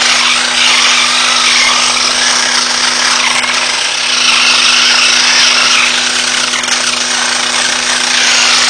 electric_motor_22KHz.wav